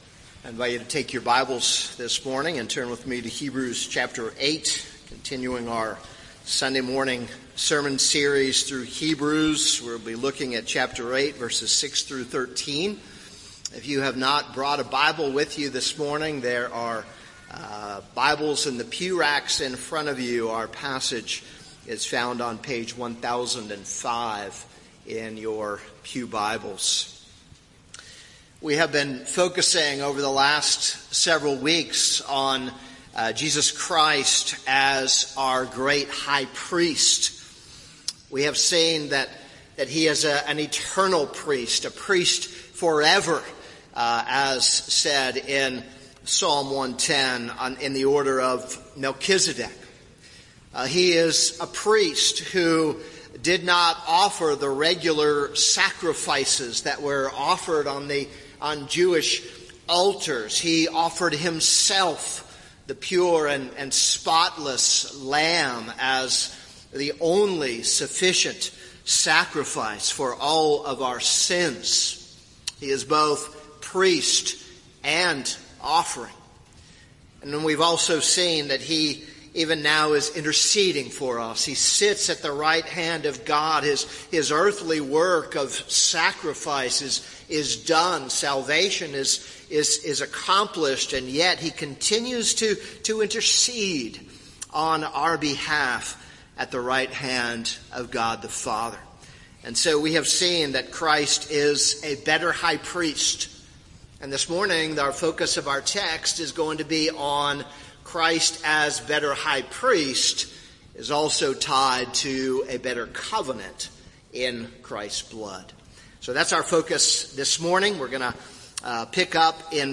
This is a sermon on Hebrews 8:6-13.